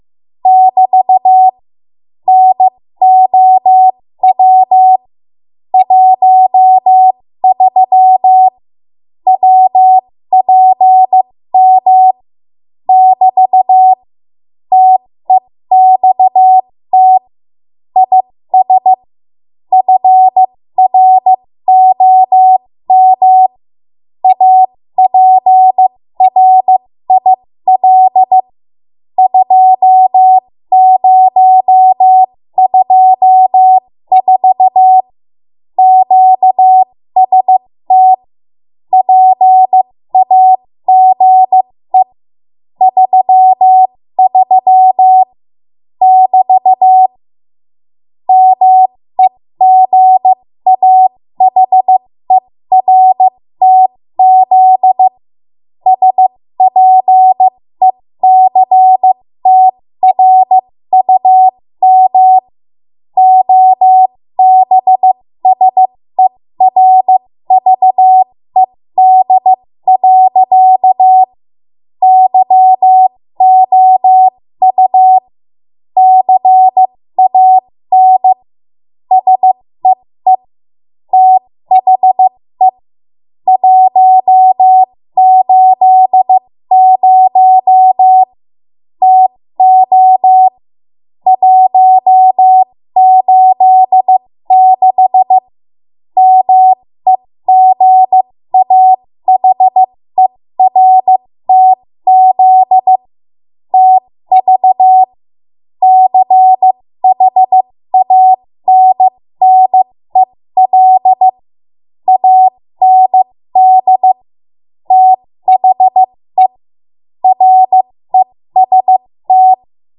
13 WPM Code Archive